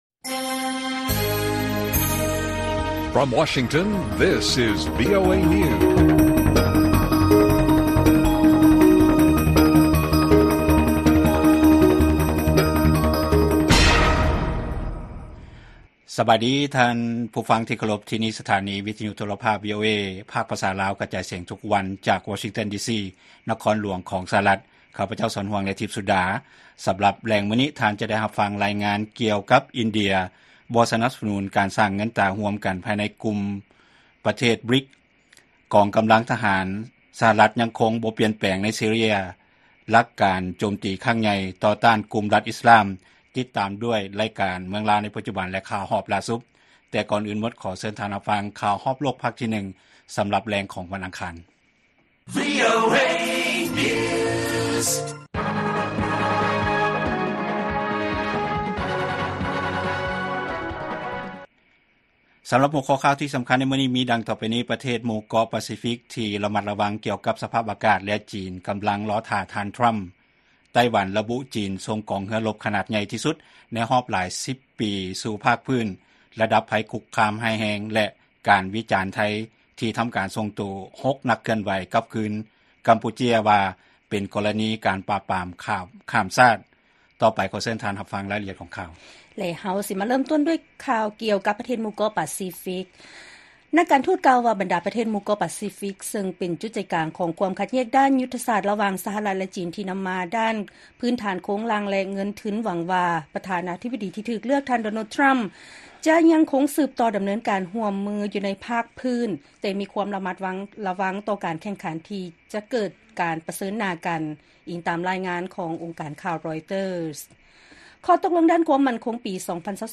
ລາຍການກະຈາຍສຽງຂອງວີໂອເອລາວ: ປະເທດໝູ່ເກາະປາຊີຟິກທີ່ລະມັດລະວັງກ່ຽວກັບສະພາບອາກາດ ແລະ ຈີນ ກໍາລັງລໍຖ້າ ທ່ານ ທຣໍາ